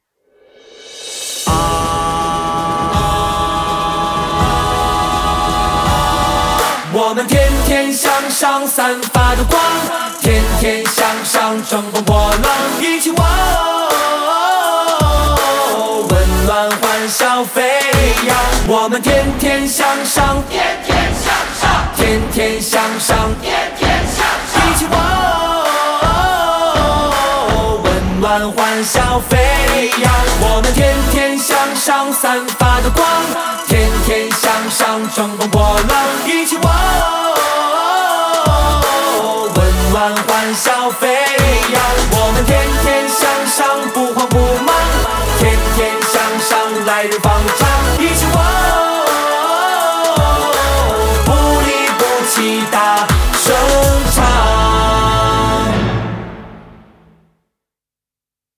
少儿拉丁舞《天天向上》
注：线上教学背景音乐，如需要，请点击附件自行下载。
天天向上（少儿拉丁舞）.mp3